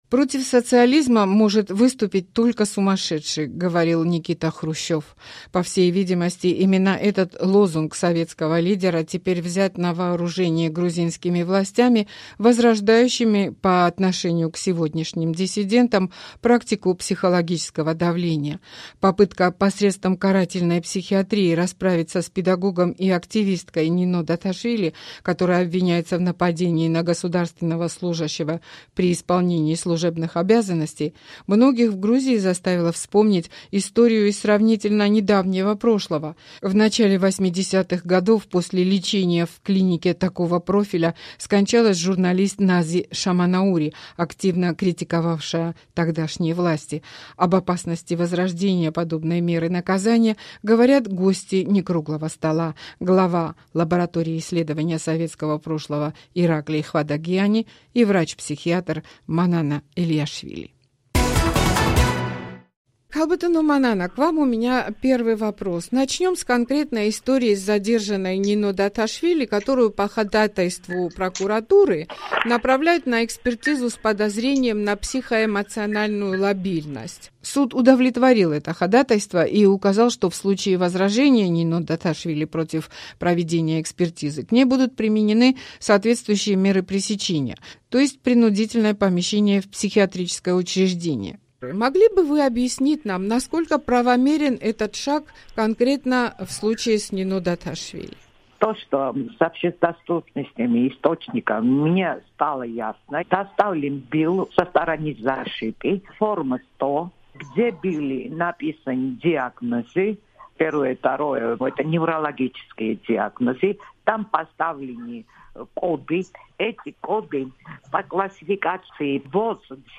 Рубрика Некруглый стол, разговор с экспертами на самые актуальные темы